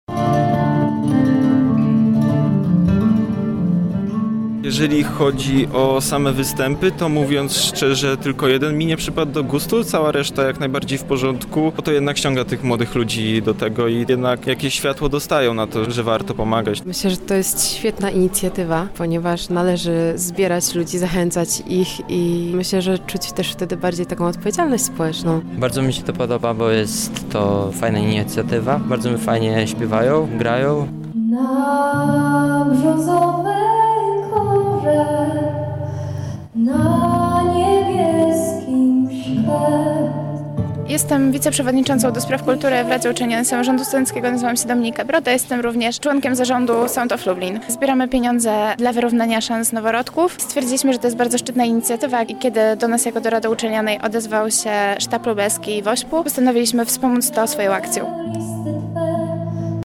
Na miejscu był nasz reporter: